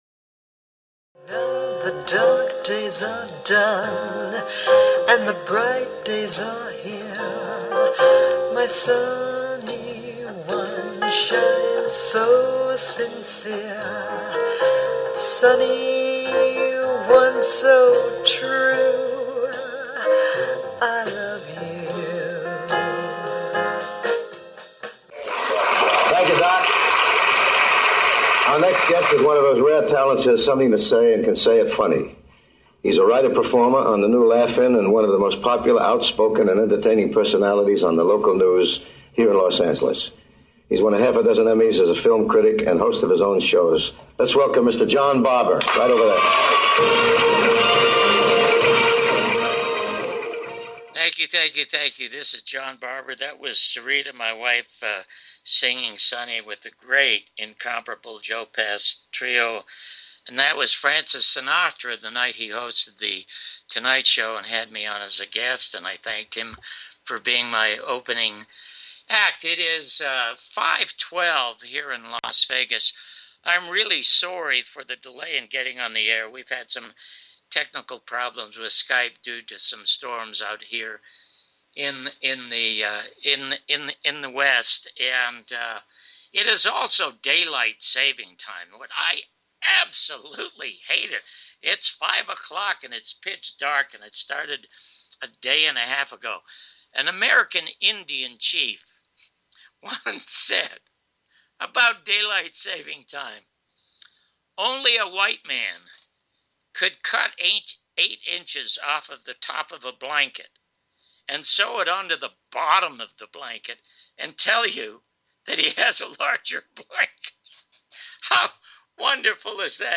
with Special Guest Cindy Sheehan
2nd half interview with Cindy Sheehan Cindy Sheehan's Soap Box